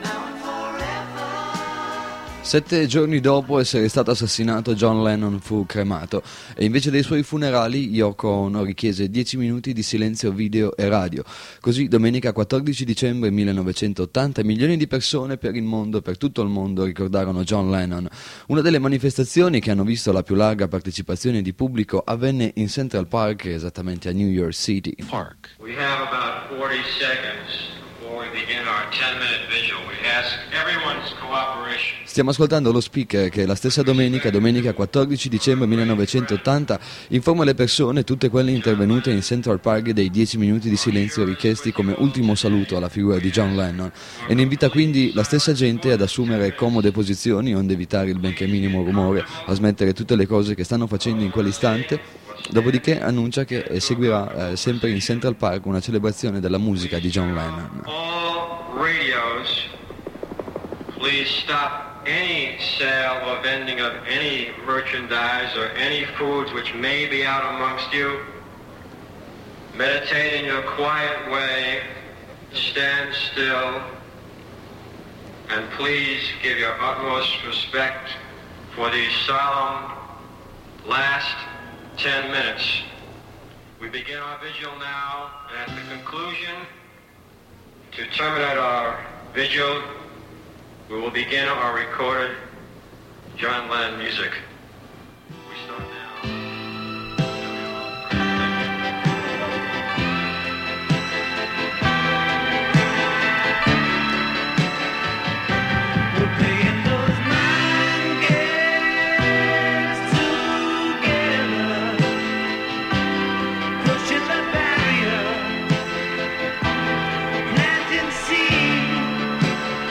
registrazione della manifestazione del 14/12/1980 a Central Park N.Y.C.